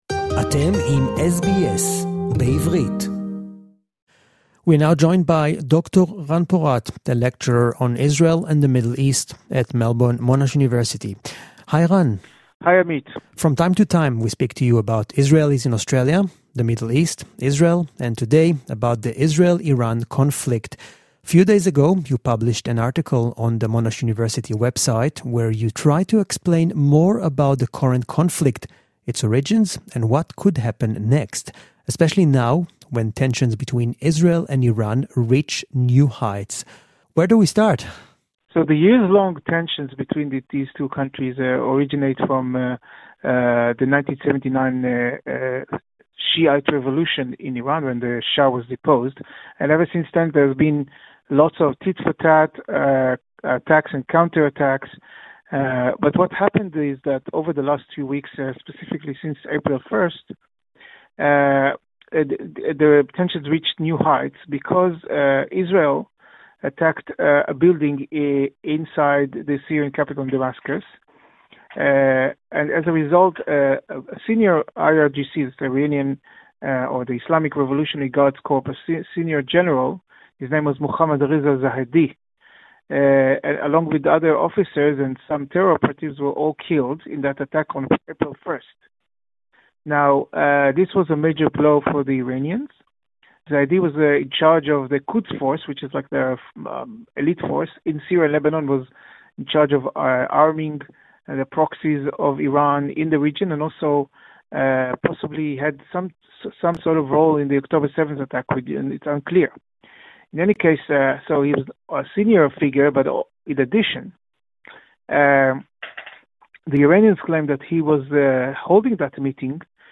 (English Interview) An Israeli counterstrike in Iran was unavoidable. Despite significant diplomatic pressures from the US and the EU, Israel retaliated on the morning of 19 April.